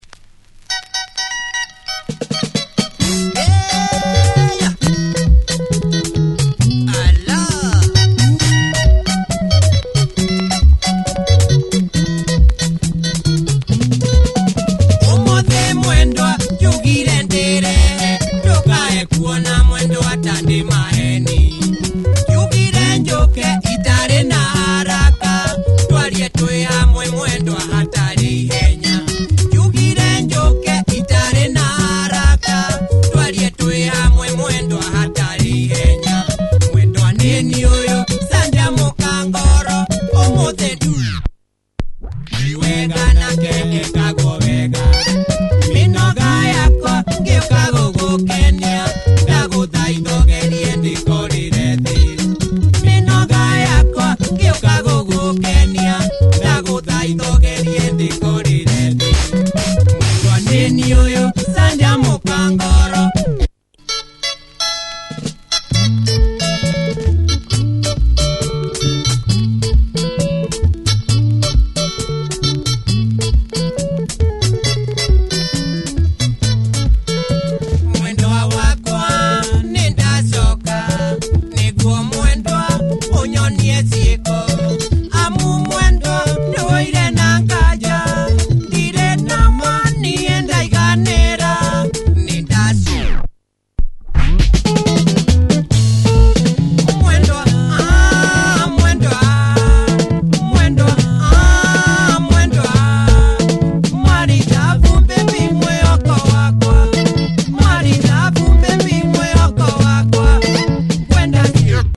Nice Bumpin’ Kikuyu benga.